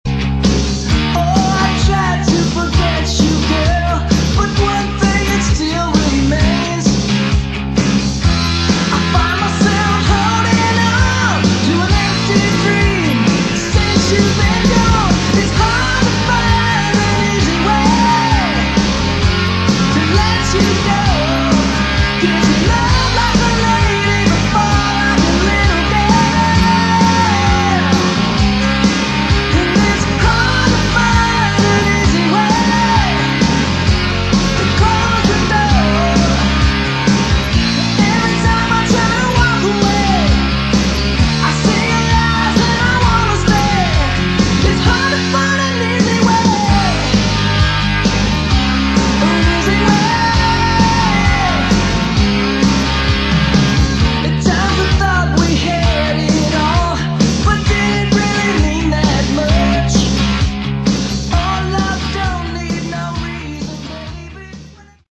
Category: AOR / Melodic Rock
Guitar, Lead Vocals
Bass, Vocals
Drums
Keyboards